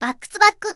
Added Zundamon voicepack